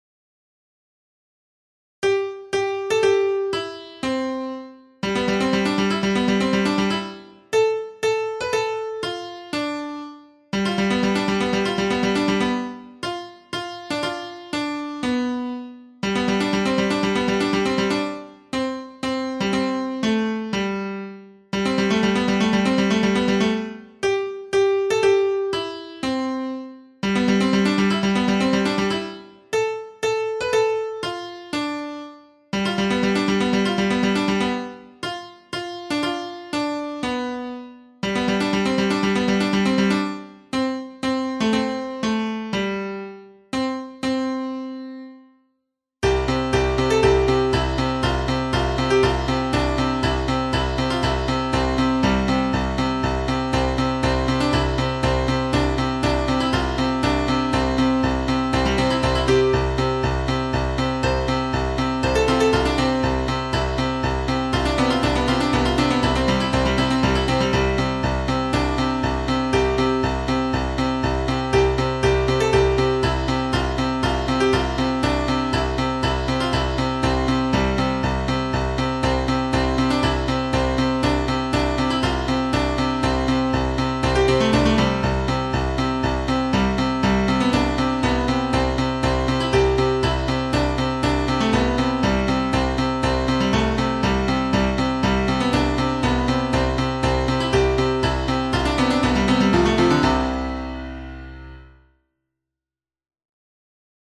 ピアノ習作１(No.015)
[15]ピアノ習作１.mp3